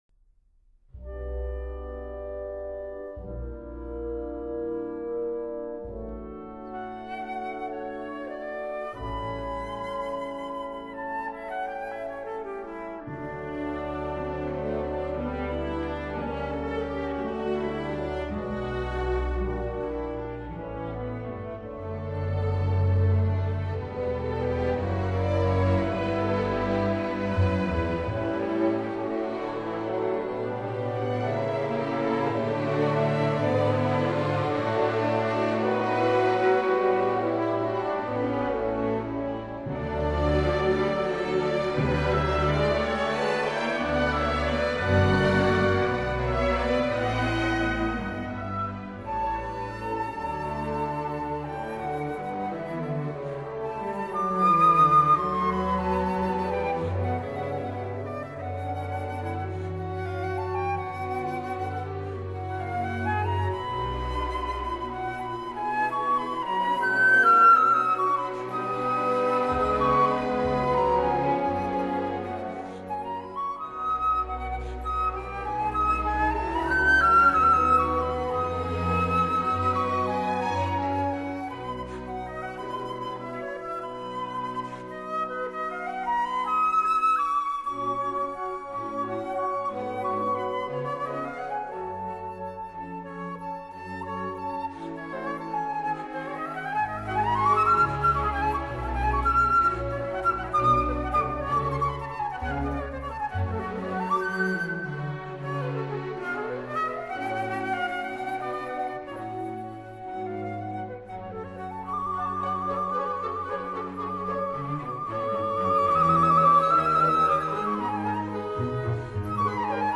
Flute Concerto - 001 Allegro molto moderato    [0:08:06.09]